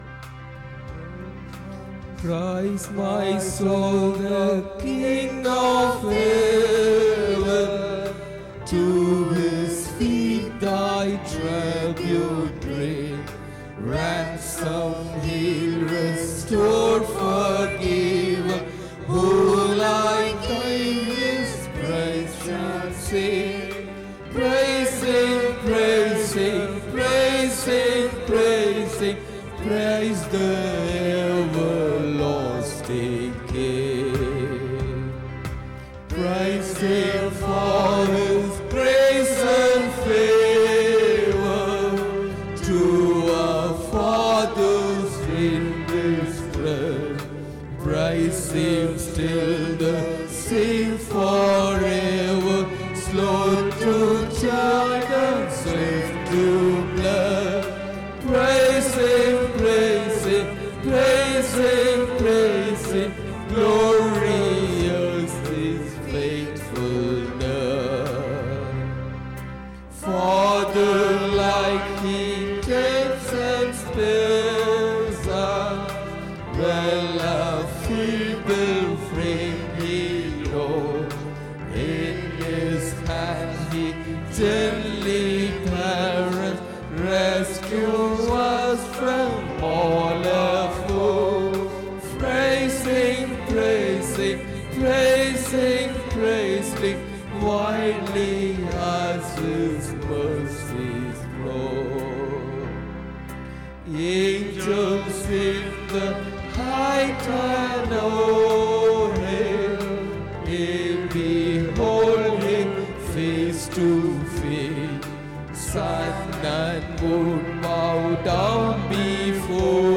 10 Aug 2025 Sunday Morning Service – Christ King Faith Mission